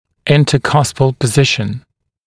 [ˌɪntə’kʌspl pə’zɪʃn][ˌинтэ’каспл пэ’зишн]положение межбугоркового смыкания